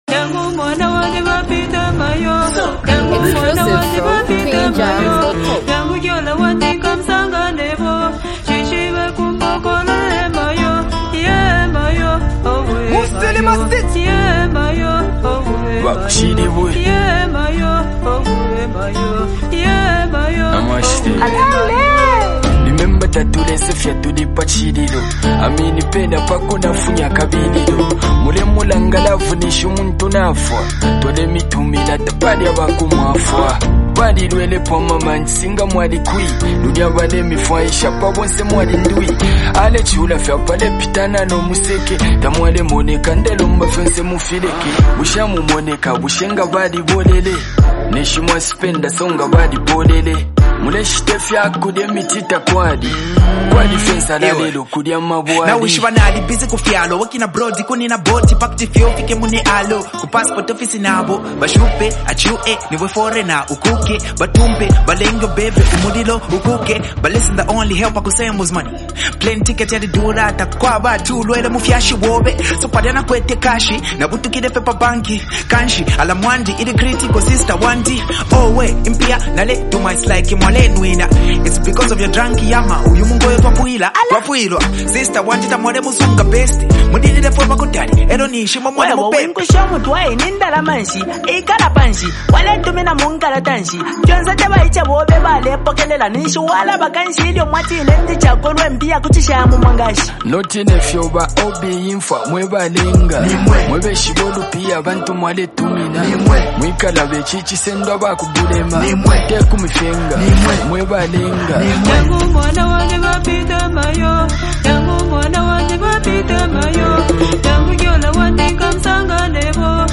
adding a soulful and spiritual layer to the track.